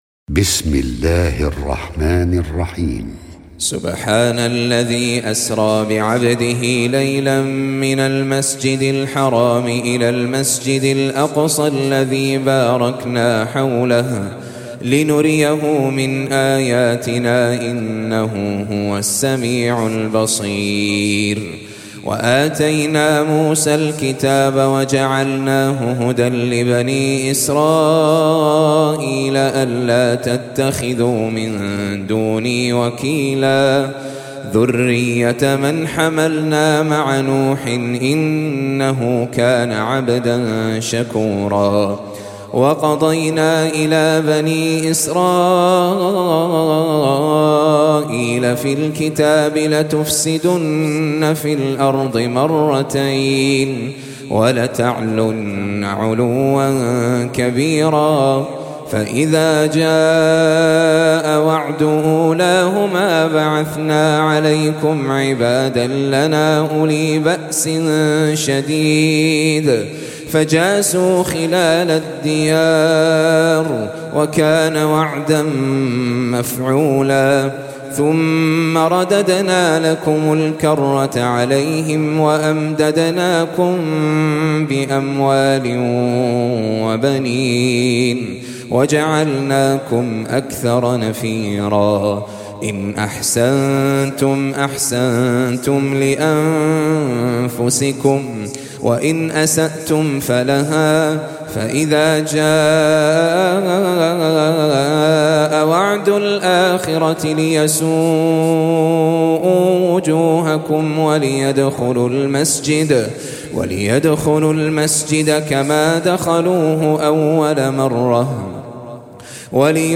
Audio Quran Tajweed Recitation
Surah Sequence تتابع السورة Download Surah حمّل السورة Reciting Murattalah Audio for 17. Surah Al-Isr�' سورة الإسراء N.B *Surah Includes Al-Basmalah Reciters Sequents تتابع التلاوات Reciters Repeats تكرار التلاوات